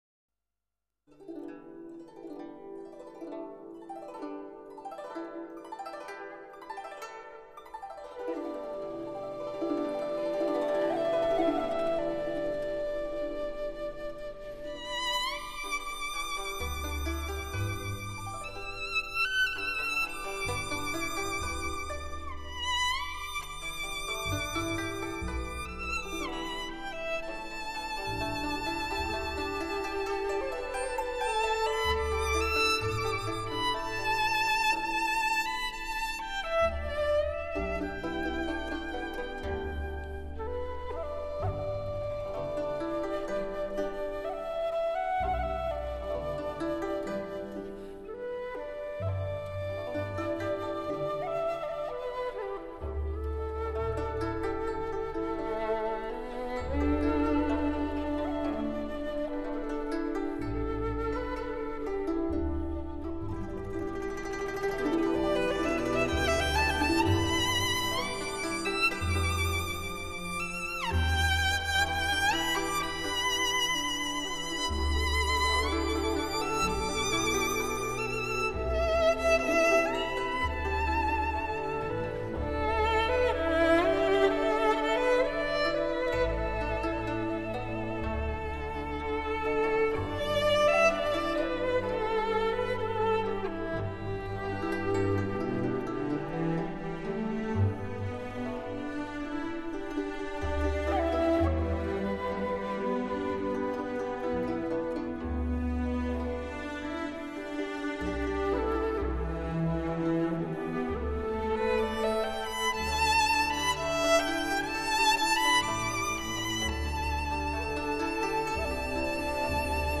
小提琴与吉他、 竹笛、古筝、人声等
音色的交织回旋，在多层次上提供听者细腻、新鲜的听觉享受。